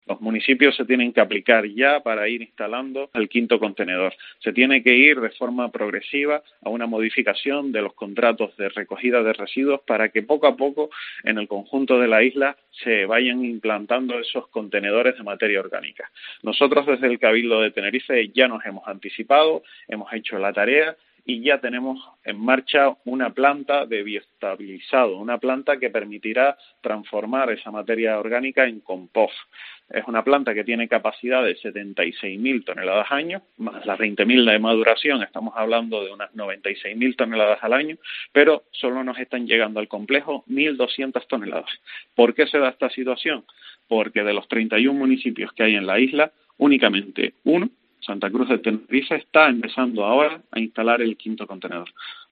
Javier Rodriguez, consejero de Desarrollo Sostenible del Cabildo de Tenerife